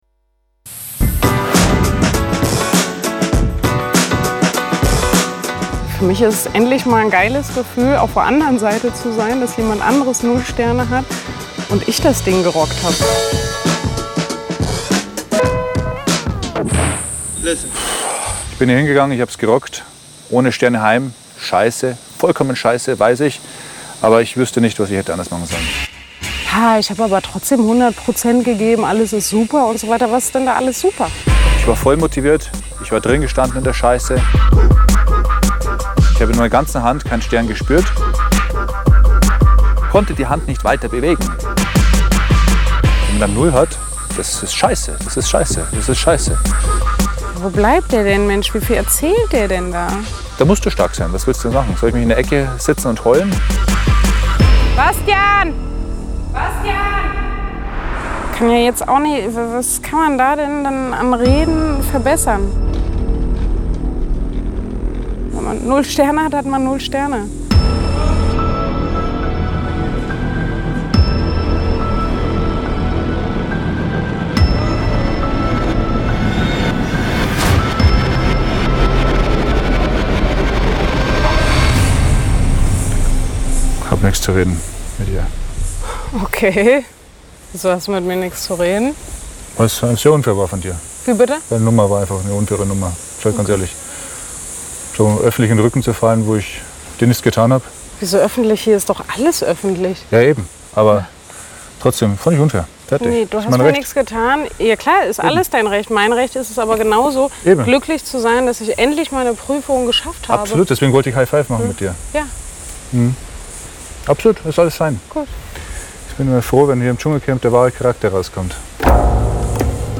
NEU! O-Töne der Dschungelstars am Tag 6
oton-tag6-dschungelcamp.mp3